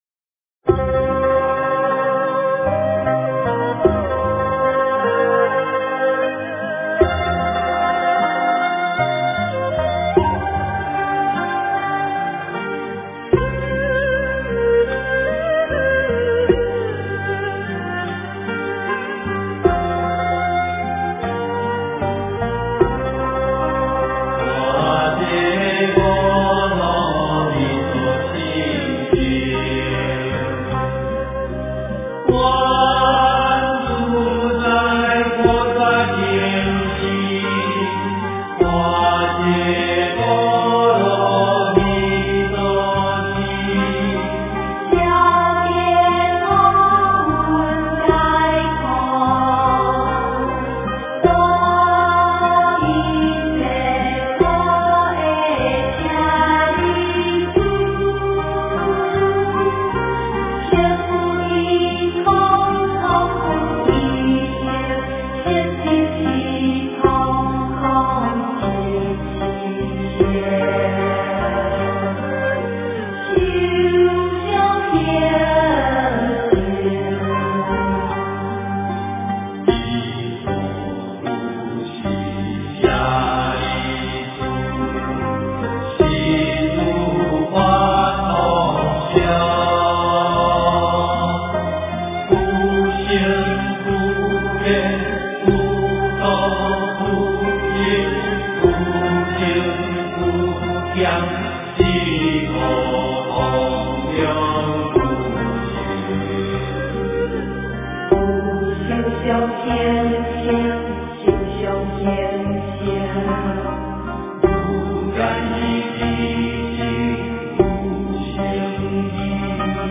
般若波罗蜜多心经 诵经 般若波罗蜜多心经--南海明灯合唱团 点我： 标签: 佛音 诵经 佛教音乐 返回列表 上一篇： 心经 下一篇： 般若心经 相关文章 《妙法莲华经》随喜功德品第十八--佚名 《妙法莲华经》随喜功德品第十八--佚名...